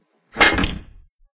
smash.ogg